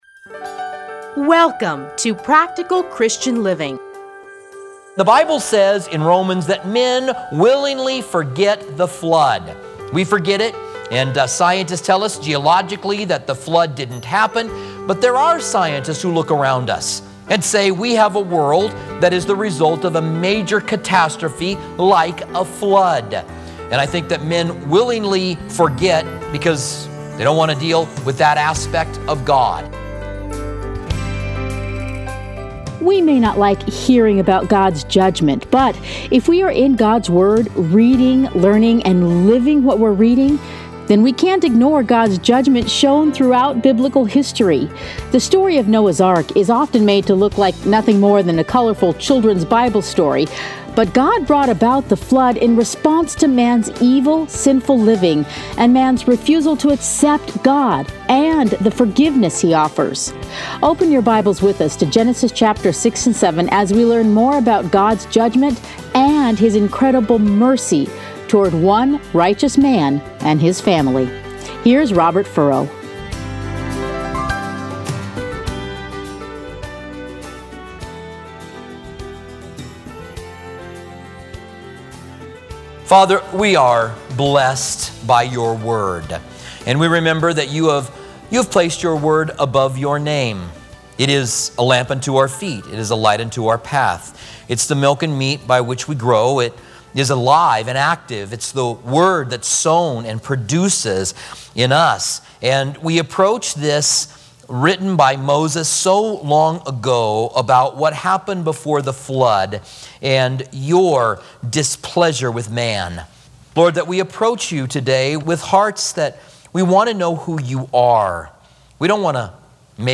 Listen here to a teaching from Genesis Playlists Commentary On Genesis Download Audio